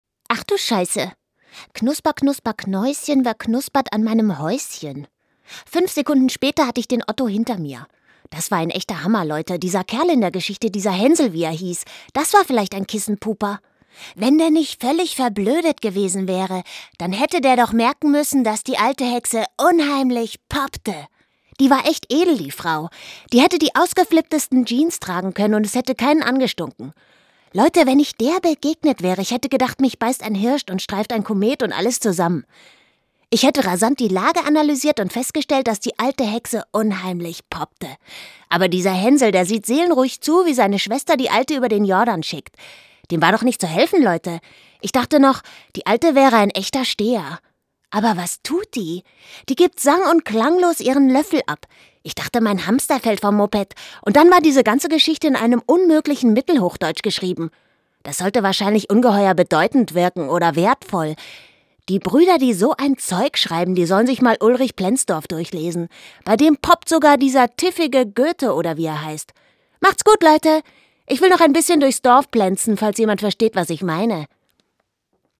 deutsche Sprecherin mit einer warmen, sinnlichen, kraftvollen, wandelbaren Stimme.
Sprechprobe: Sonstiges (Muttersprache):